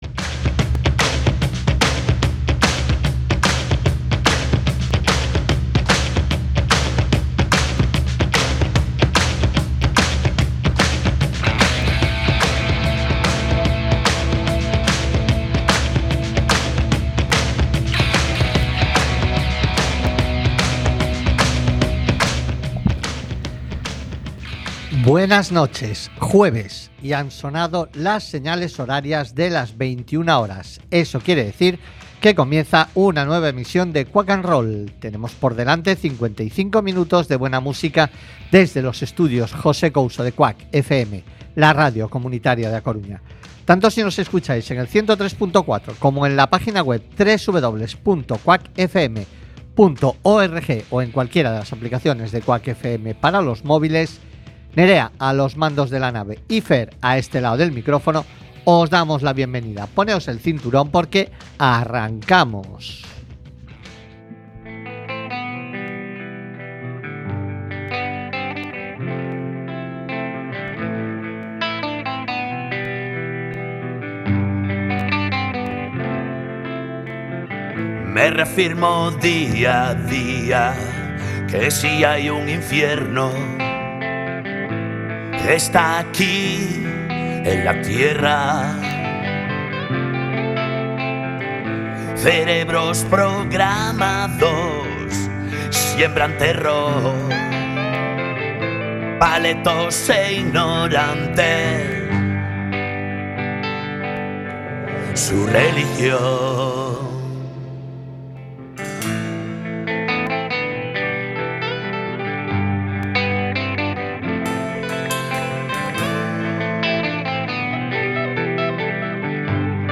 Programa musical que huye de las radioformulas y en el que podreis escuchar diversidad de generos... Rock , Blues , Country, Soul , Folk , Punk , Heavy Metal , AOR...